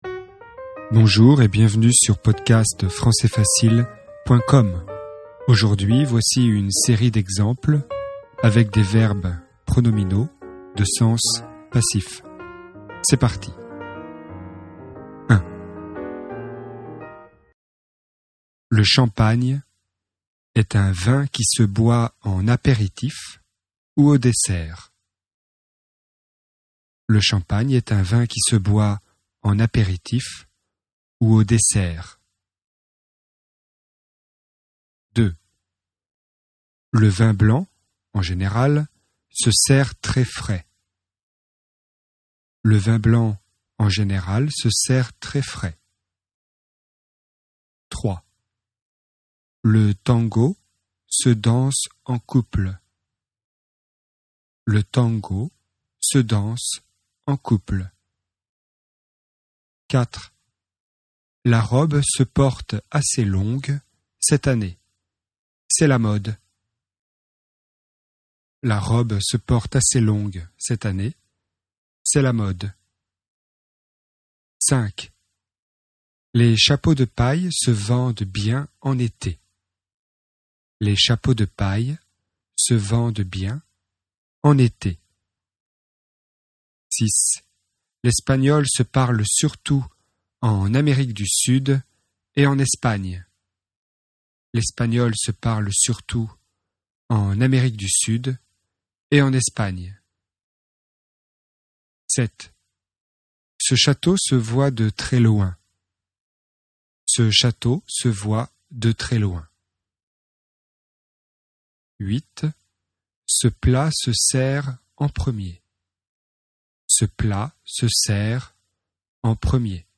Leçon grammaire, niveau intermédiaire (B1), sur le thème des verbes pronominaux.